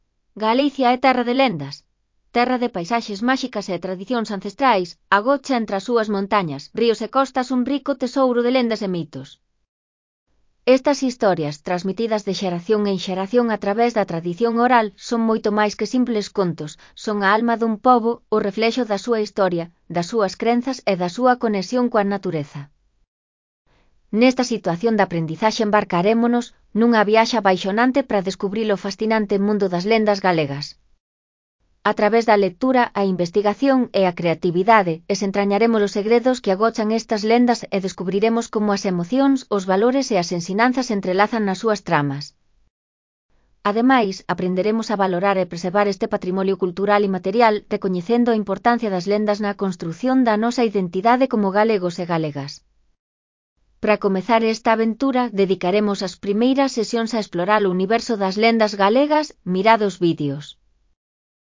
Lectura facilitada
Elaboración propia (proxecto cREAgal) con apoio de IA voz sintética xerada co modelo Celtia.